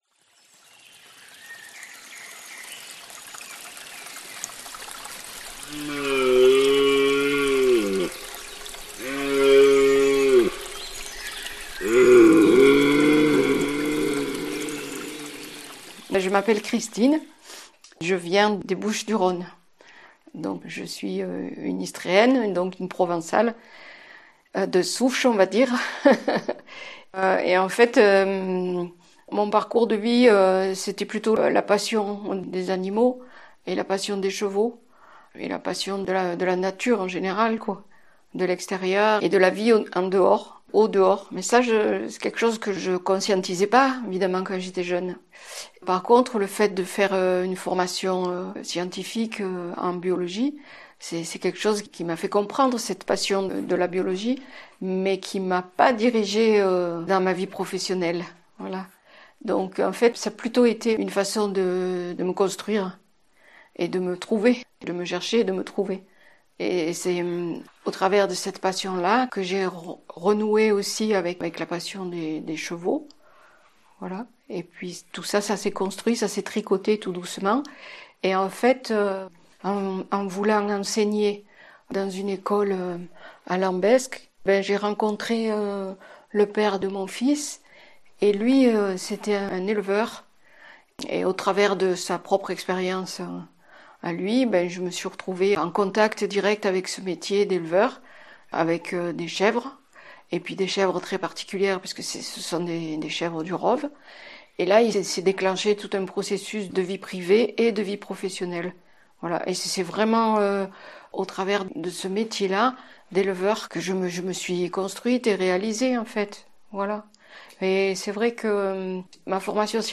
chevrière